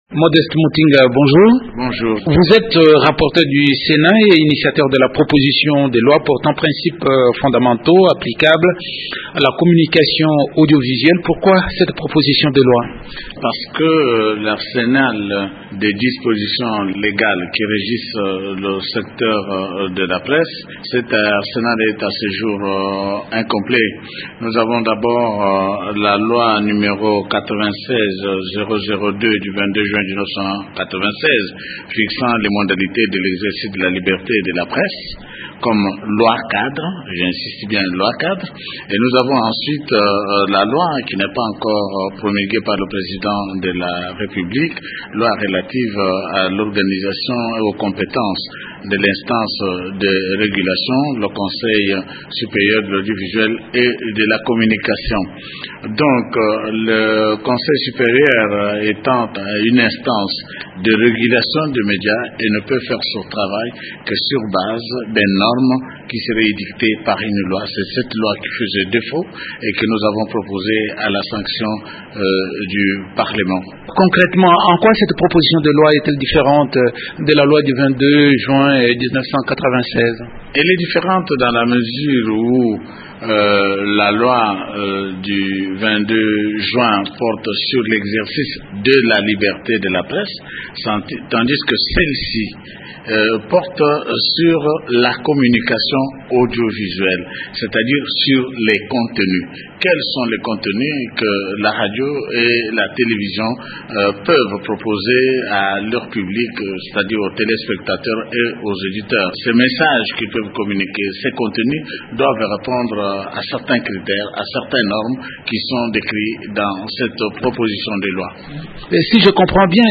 Modeste Mutinga, rapporteur du Senat